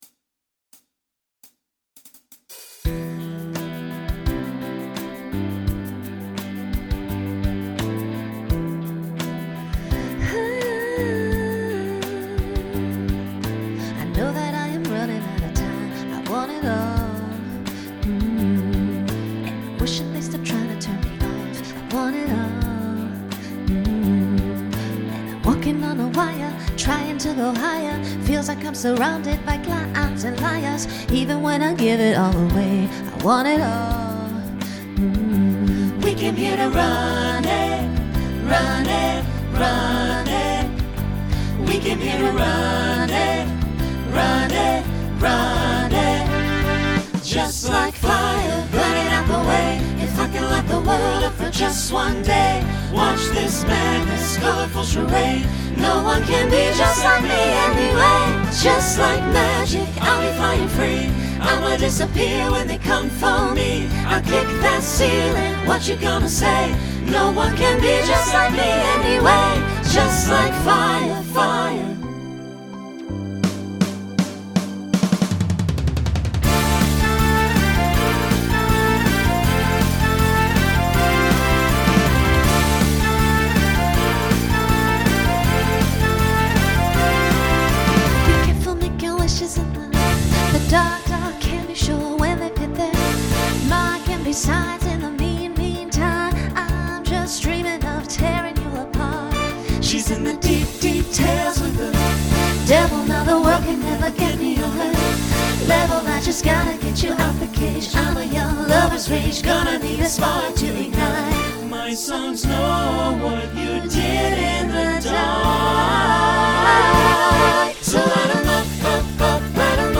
Pop/Dance , Rock
Voicing SATB